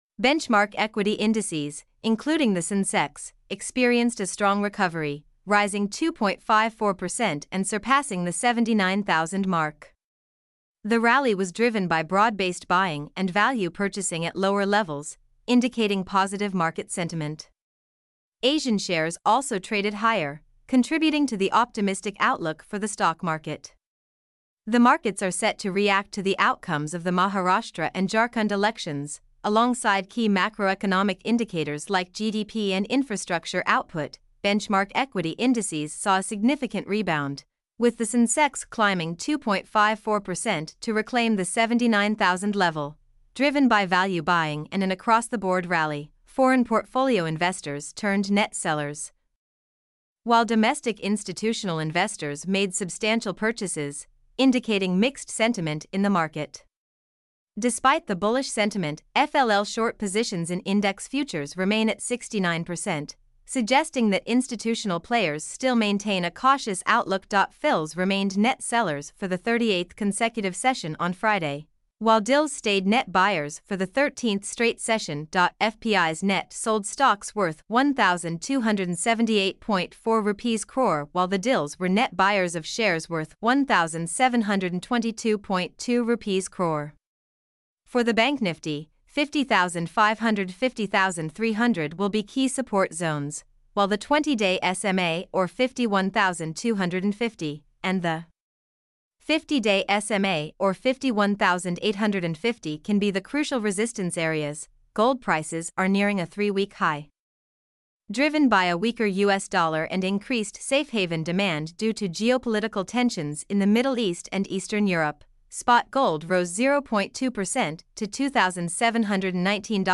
mp3-output-ttsfreedotcom-4.mp3